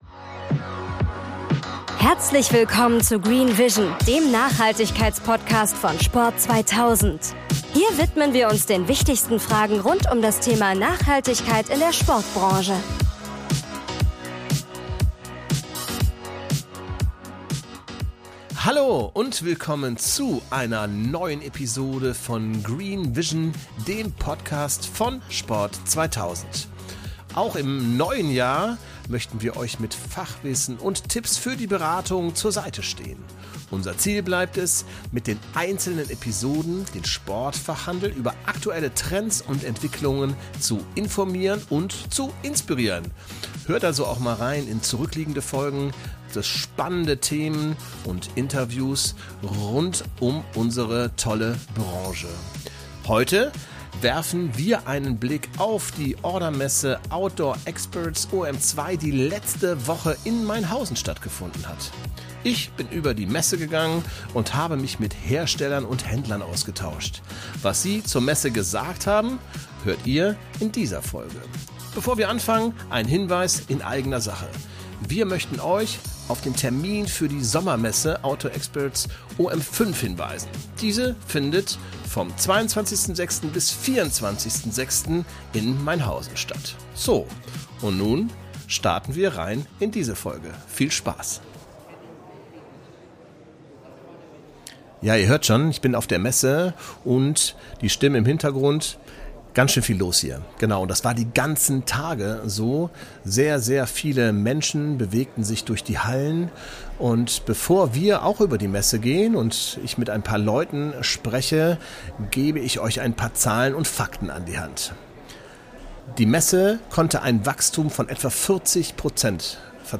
Ein Stimmungsbericht im Audioformat, mit zahlreichen Stimmen aus Handel und Industrie.